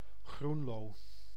Groenlo (Dutch pronunciation: [ˈɣrunloː]